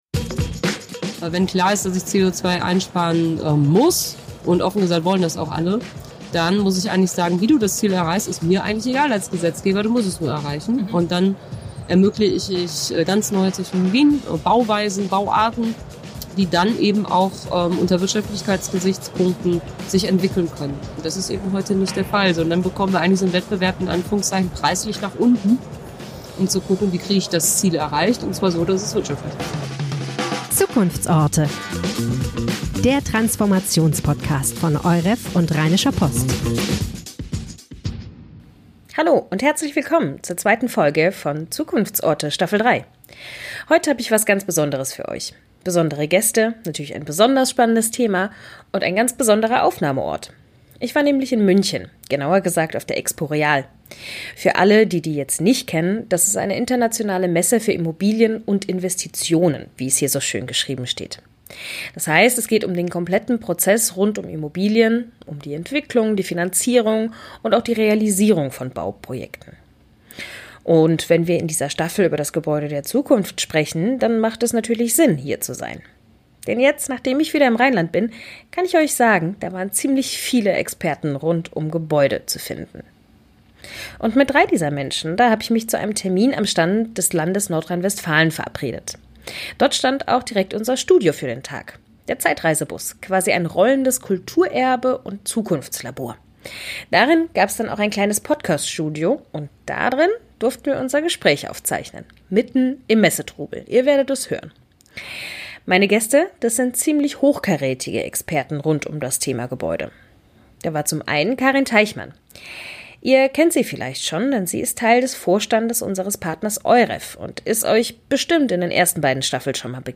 Live von der Expo Real: Wie kann nachhaltig Bauen einfacher werden? ~ Zukunftsorte - der Transformationspodcast von Euref und Rheinischer Post Podcast
Die Zahl der genehmigten Bauanträge geht seit Jahren zurück, gleichzeitig gibt es immer strengere Umweltvorgaben. Ein Interview mit Ina Scharrenbach